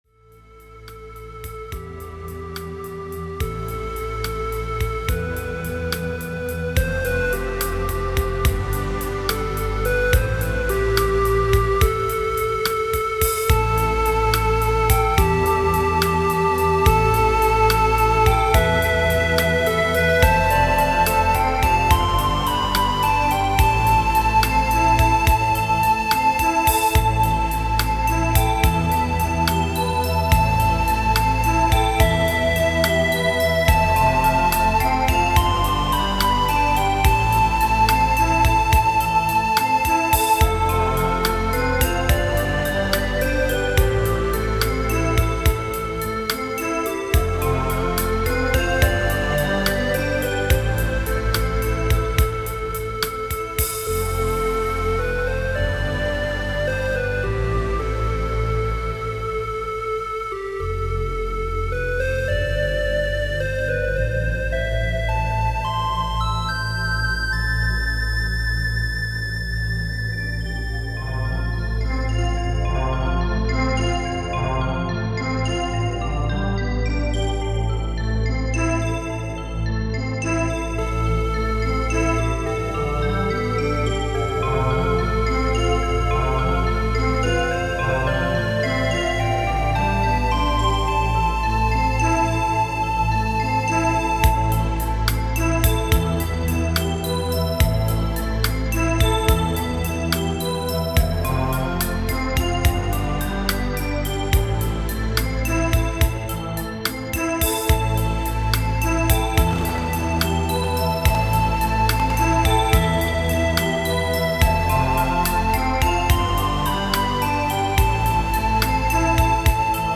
Ambient Stuff: "Ambient Dreams"
This is a more relaxing new age like tune. I like the clear pads.
MIDI at its best yet again! some of the soundfonts you are using and the effects are just amazing!
I used only the 8 MB Chaos-Soundfont and for the reverb I used the presets from my SBLIVE and then recorded the Song with Cooledit.